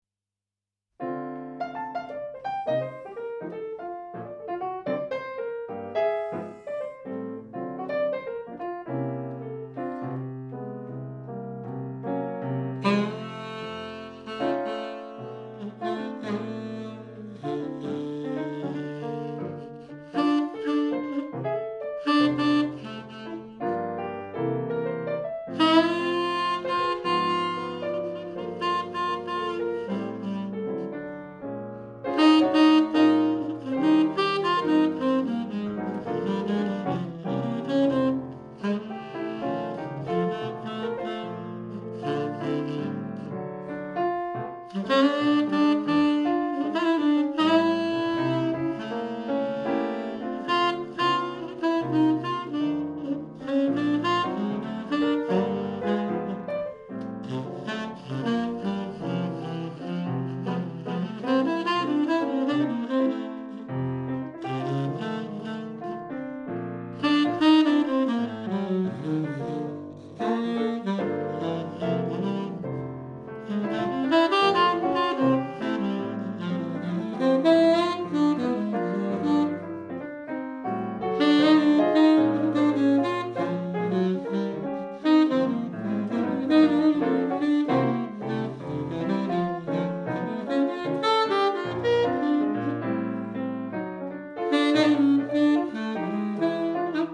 Natural Sound Recording
爵士音樂/發燒天碟